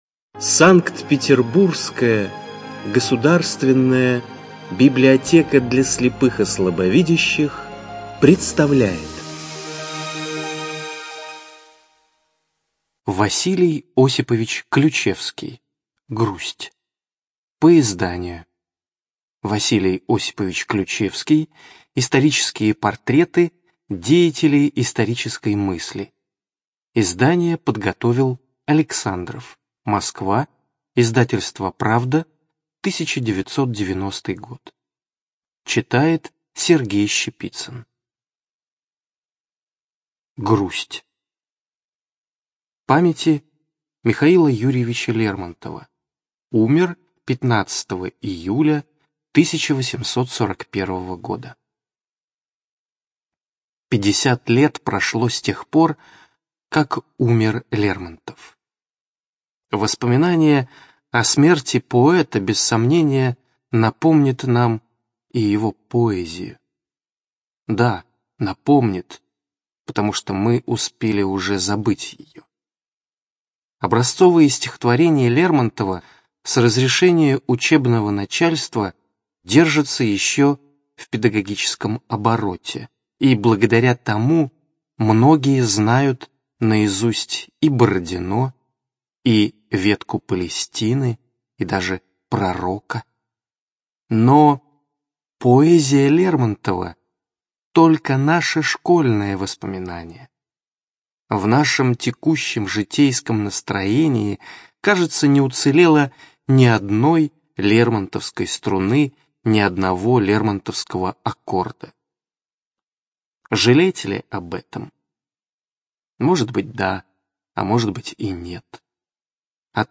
ЖанрПублицистика
Студия звукозаписиСанкт-Петербургская государственная библиотека для слепых и слабовидящих